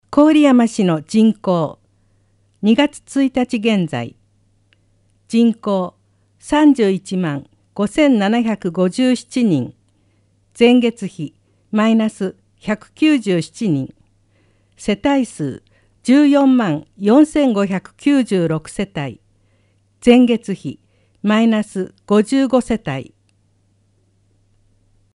「声の広報」は、「広報こおりやま」の一部記事を「視覚障がい者支援ボランティアグループ　くるみ会」の皆さんが読み上げています。